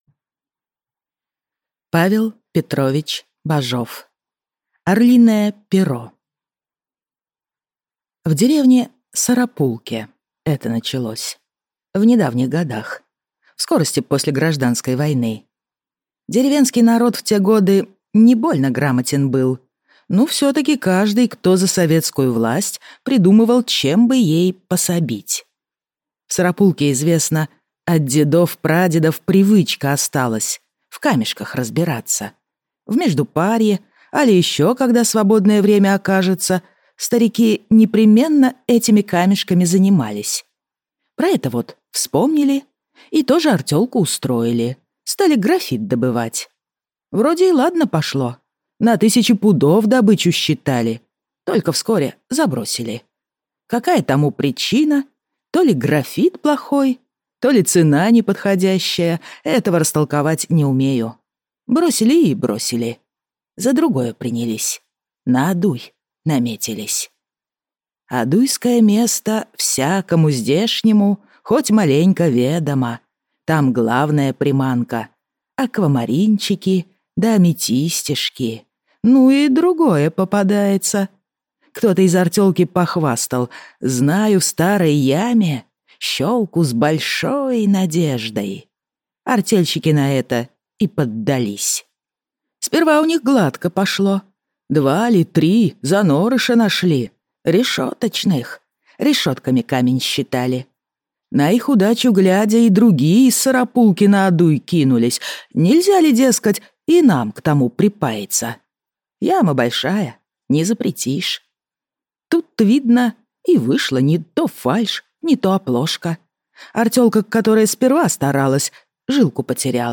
Аудиокнига Орлиное перо | Библиотека аудиокниг